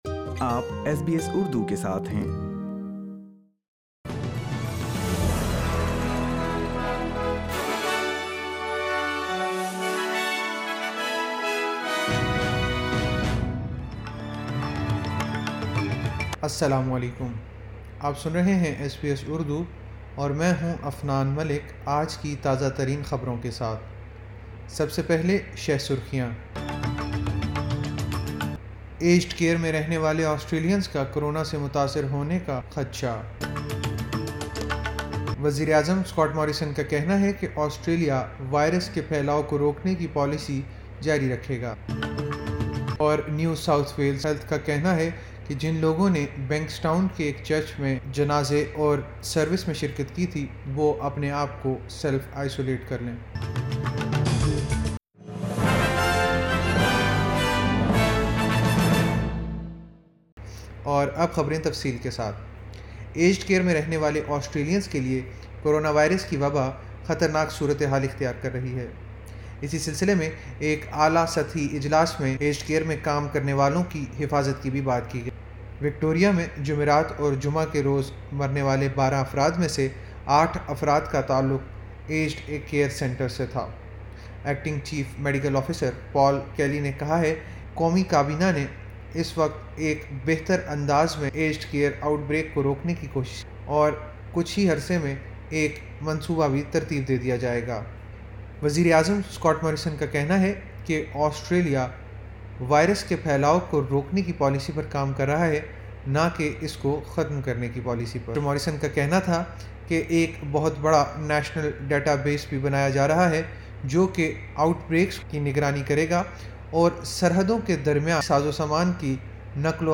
ایس بی ایس اردو خبریں 25 جولائی 2020